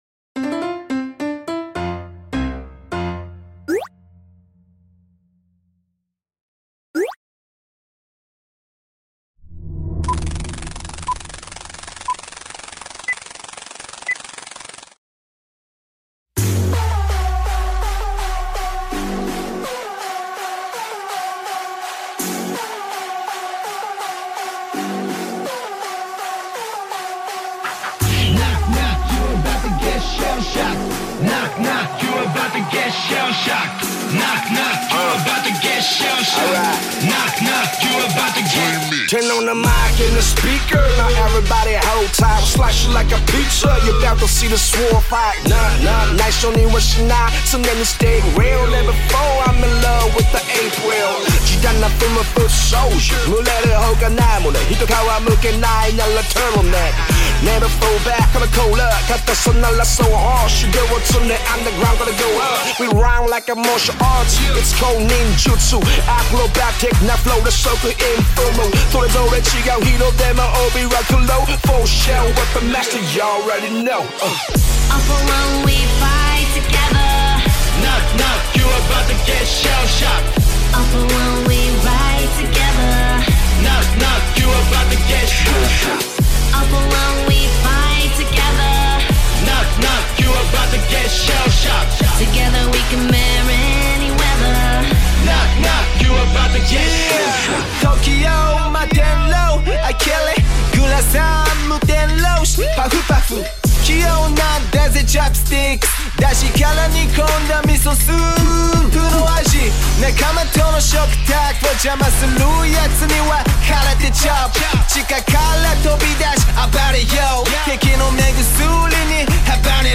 Awesome Japanese Rap Lyrics